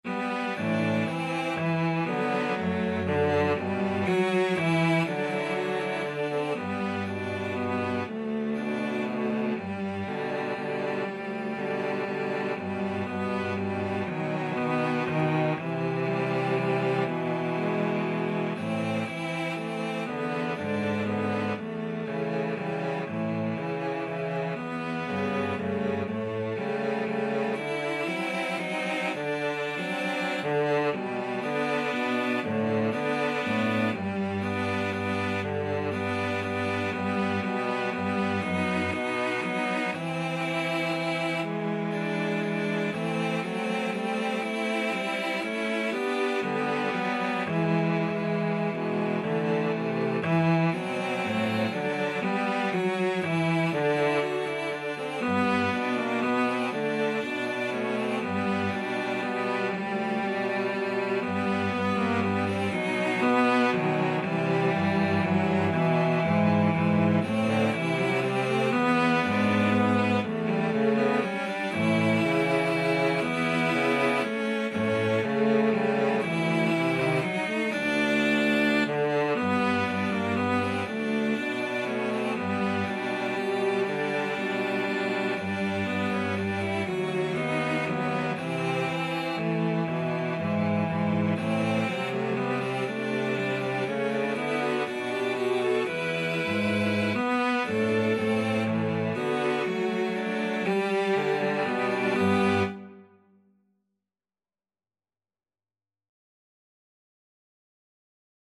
3/4 (View more 3/4 Music)
= 120 Tempo di Valse = c. 120
Cello Trio  (View more Intermediate Cello Trio Music)
Jazz (View more Jazz Cello Trio Music)